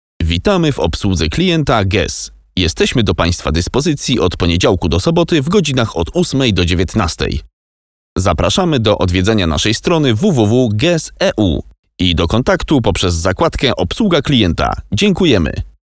Flexible, energetic and charismatic voice.
Telephony